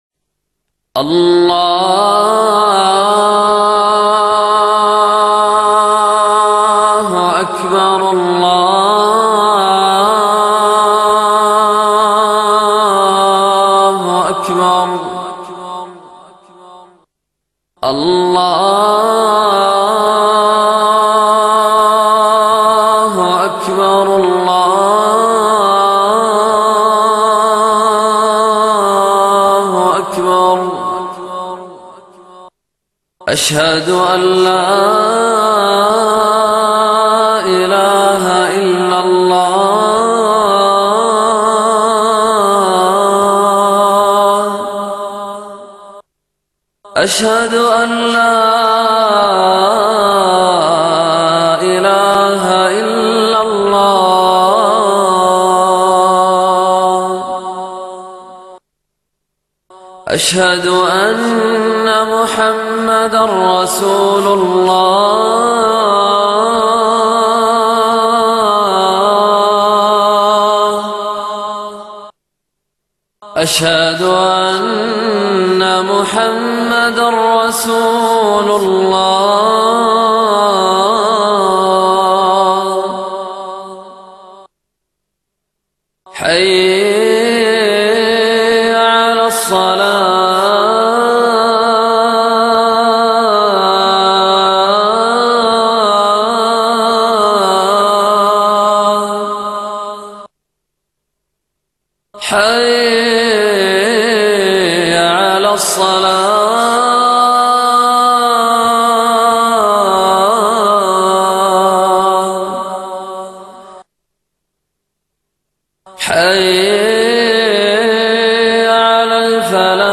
المكتبة الصوتية روائع الآذان المادة آذان